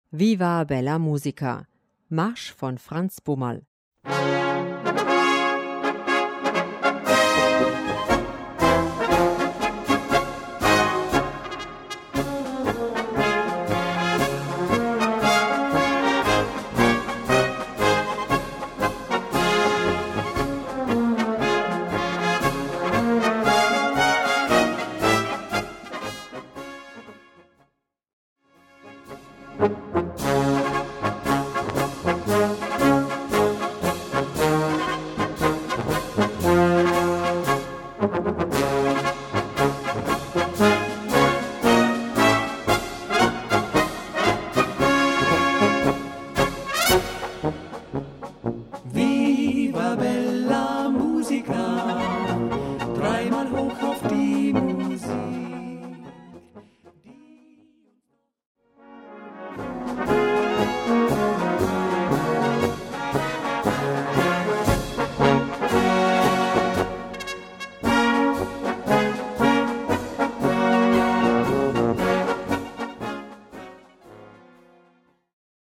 Gattung: Marsch
Besetzung: Blasorchester
rhythmisch typisch böhmisch
mit einem virtuosen Basssolo